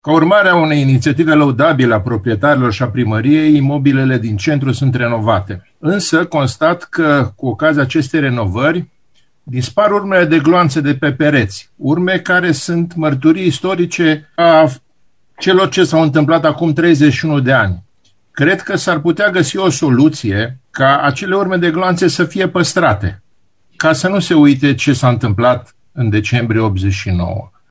Într-o intervenție în ședința de Consiliu Local de ieri